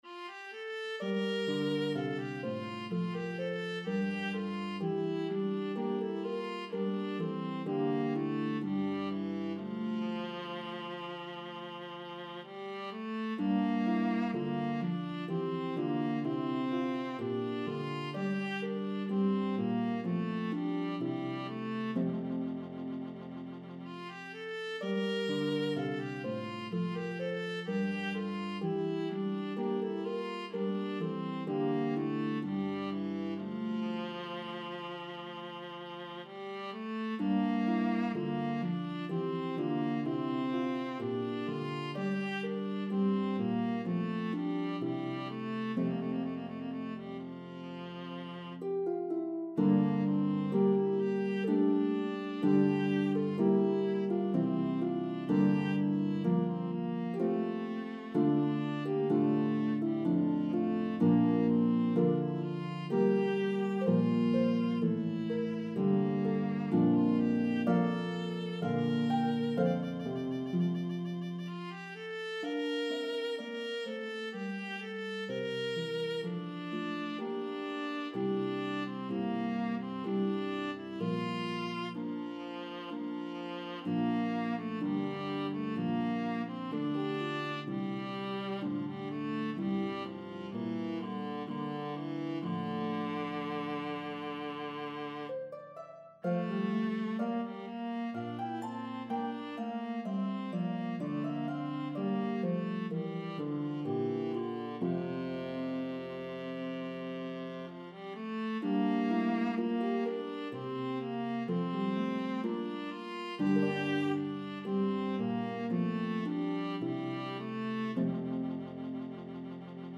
Italian Baroque style pieces
lovely slow air